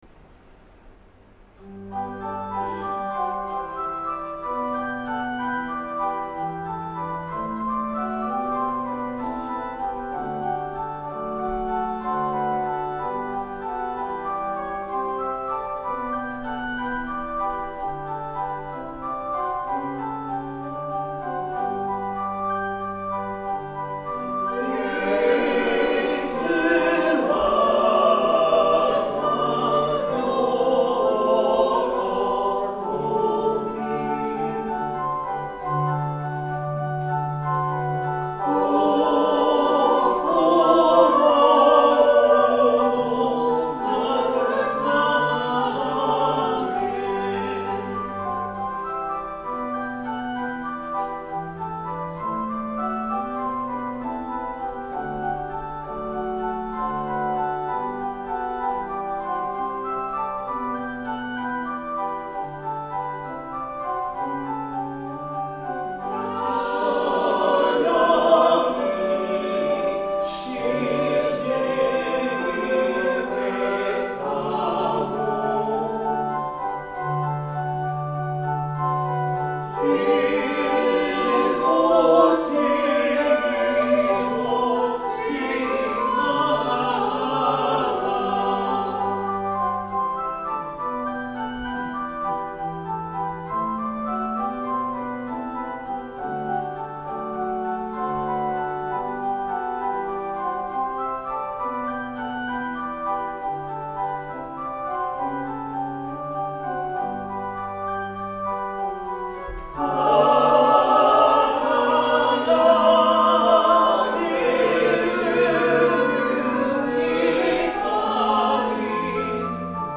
♪聖歌隊練習補助音源
Tonality = G　Pitch = 440　Temperament =Equal
1　 Organ with reverberation (S-H)